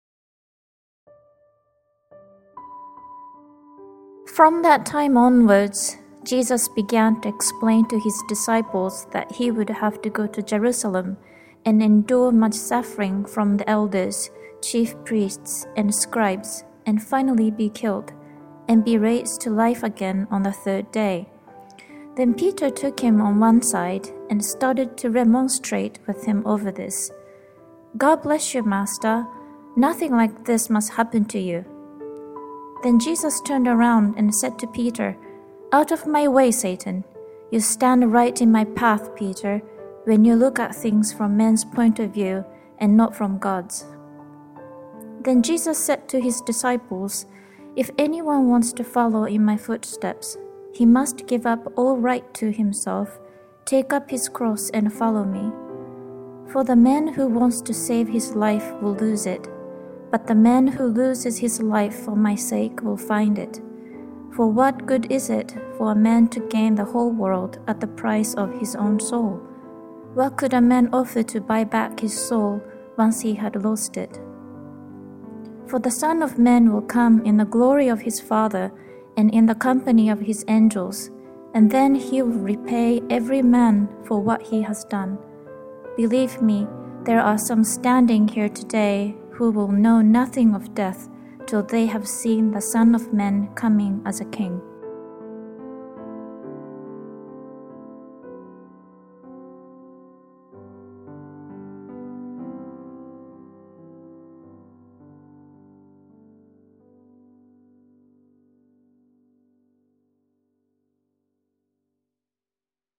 reads part of Matthew 16 as part of the 2015 Bible Challenge.